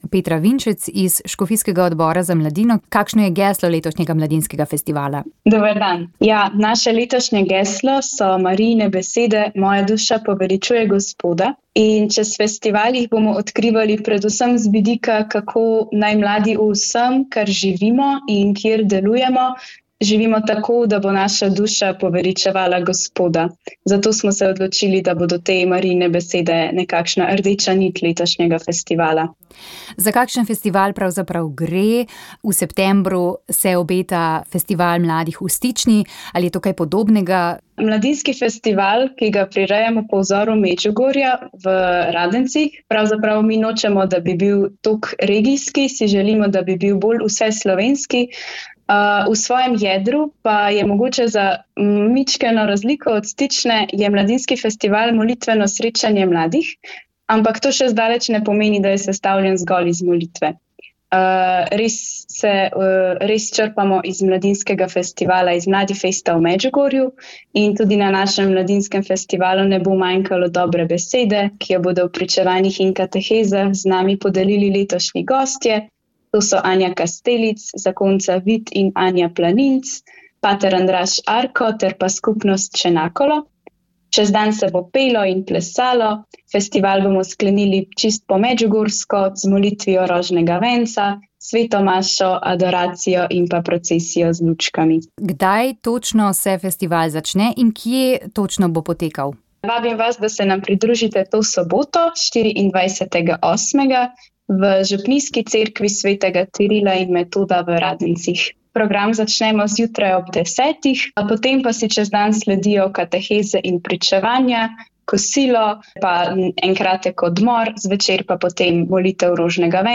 Slišali smo tudi dve pričevanji oseb, ki sta hvaležni, da sta prišli v skupino.